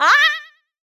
miss.wav